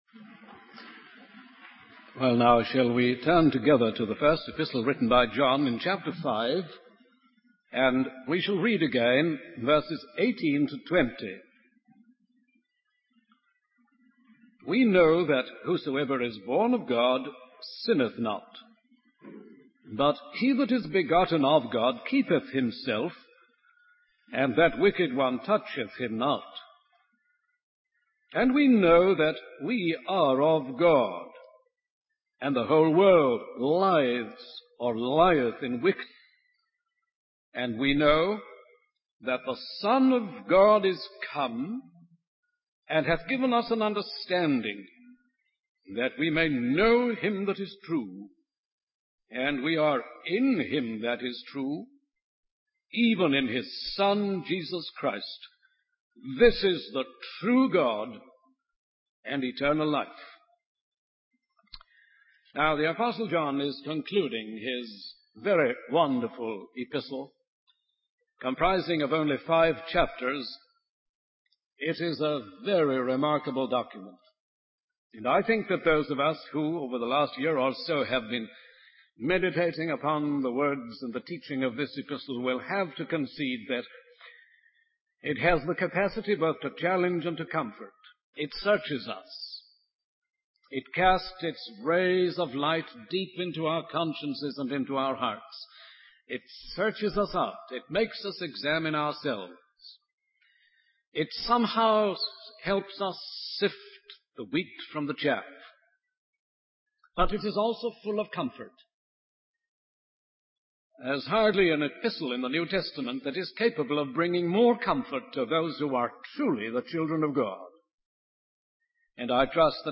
In this sermon, the Apostle John concludes his epistle by presenting a cluster of certainties to challenge and comfort the readers. He emphasizes the importance of knowing the true God who can save and transform souls.